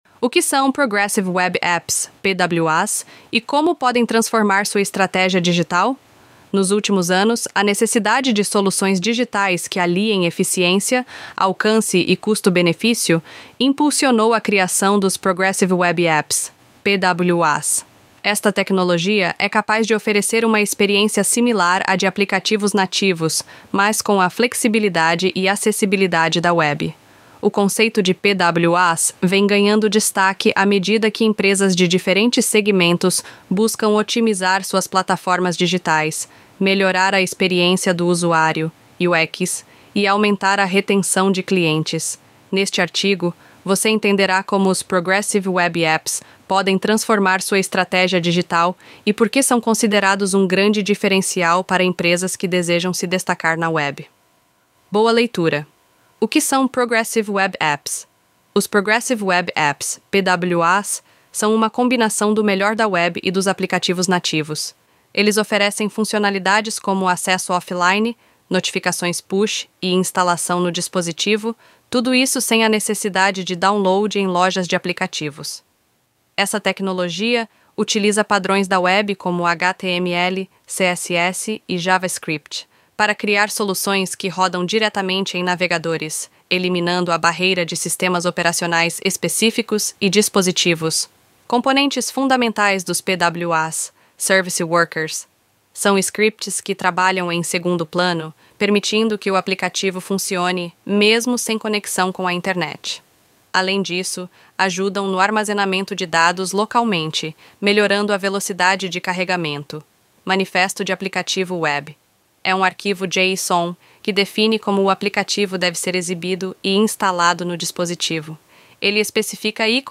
Texto-sobre-Progressive-Web-Apps-Narracao-Avatar-Rachel-ElevenLabs.mp3